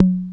1 Foyer Drum Machine.wav